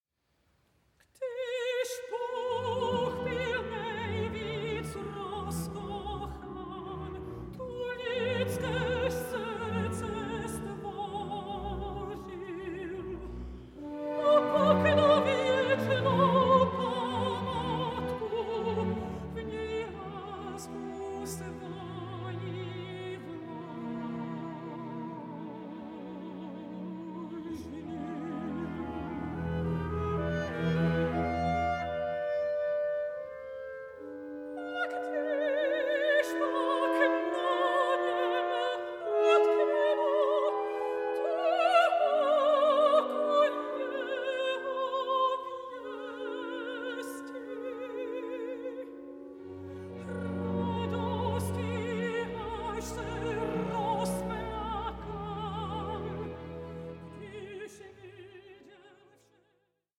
mezzo-soprano